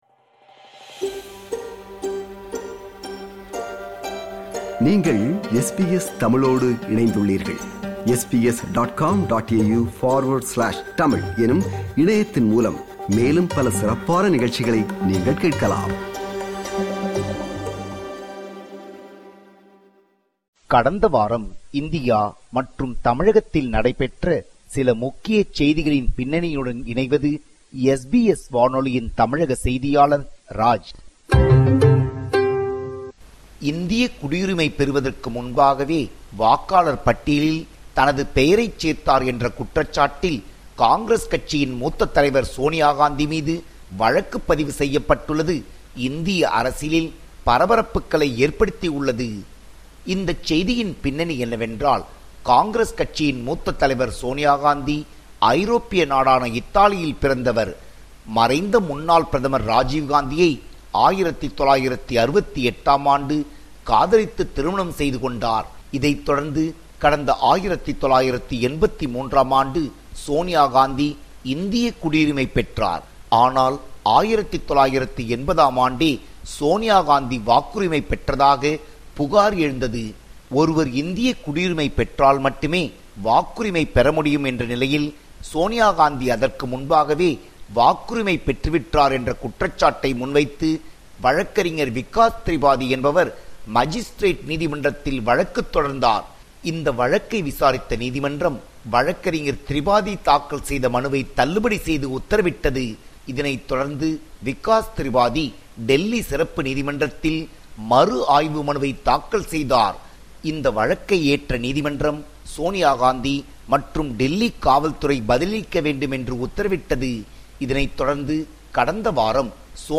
இந்தியா & தமிழ்நாடு: கடந்த 7 நாட்கள் செய்திகளின் தொகுப்பு